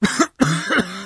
cough1.ogg